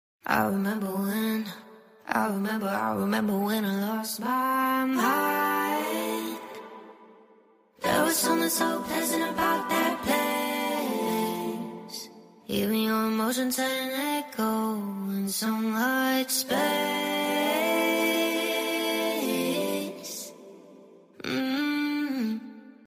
Binaural Beats Brain Reset!